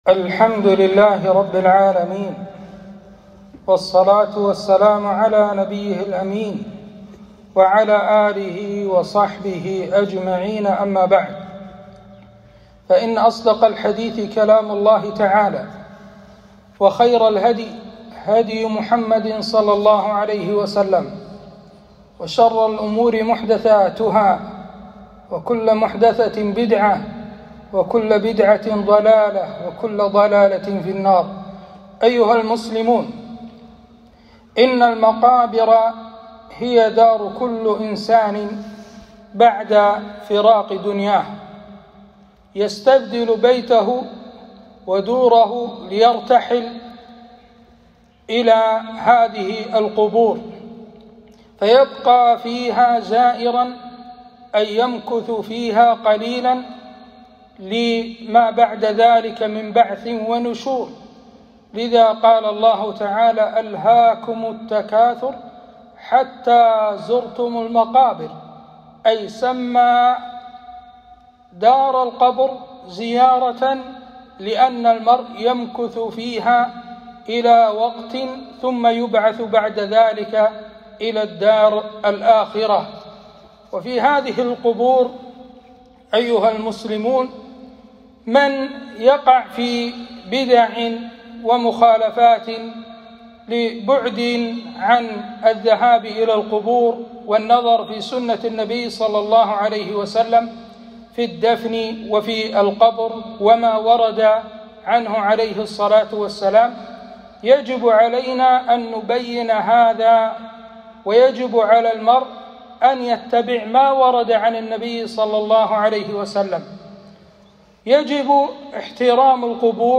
خطبة - زيارة القبور بين المشروع والممنوع